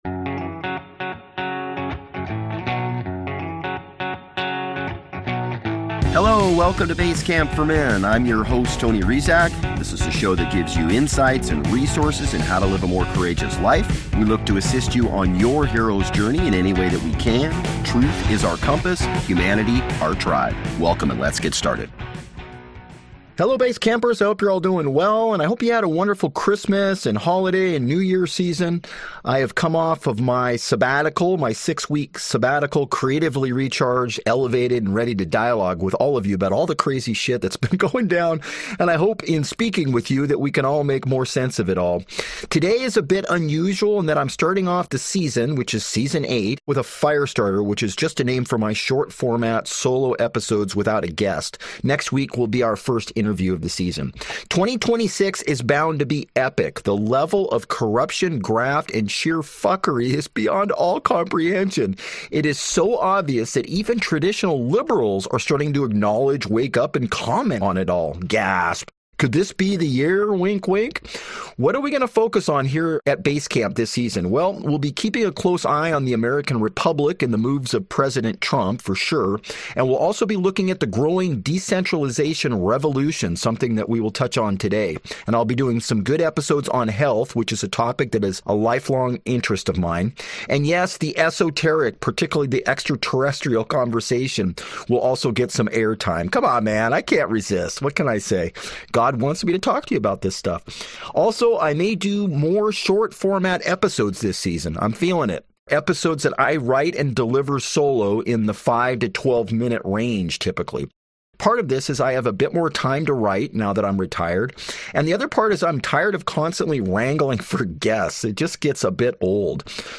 Join Basecamp as they kick off Season #8 with a solo episode about the power of decentralized systems.